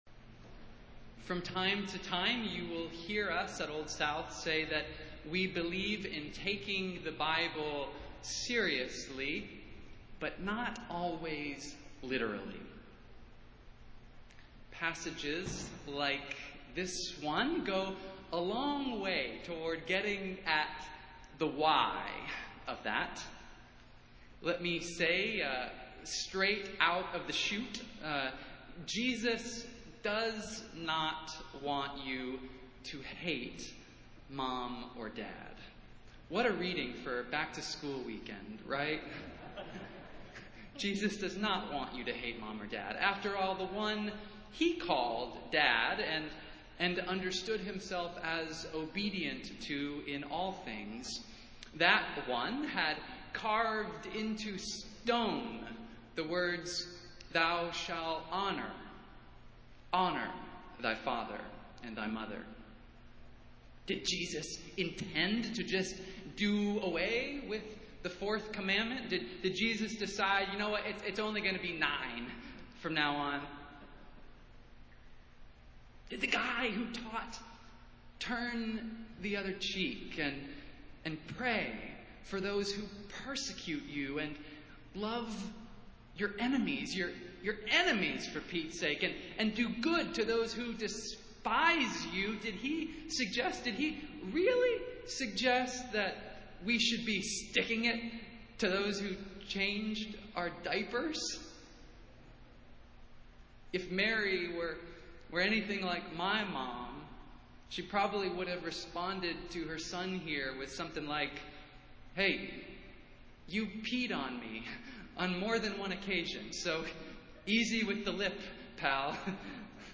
Festival Worship - Sixteenth Sunday after Pentecost